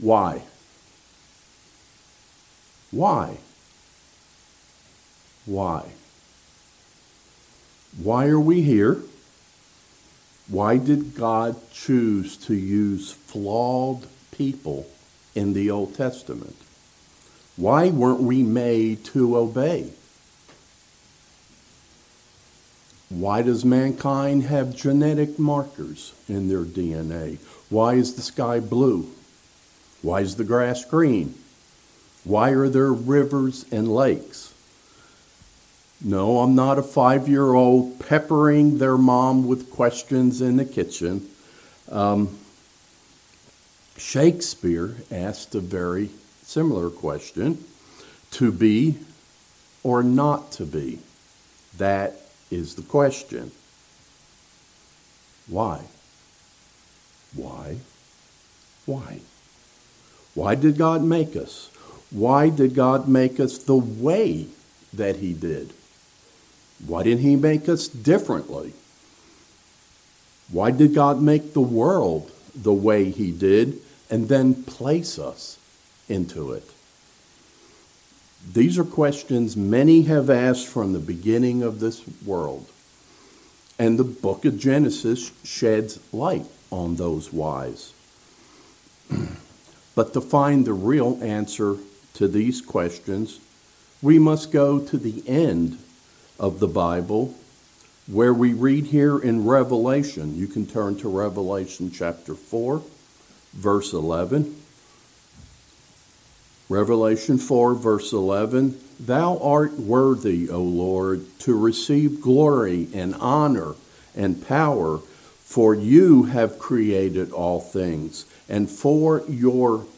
This ambitious sermon series, is going to be an expository, --on a very specific section of man's time on this planet—and analyzing, how Gods design and intimate involvement—has at its very core, His great love and concern for all mankind.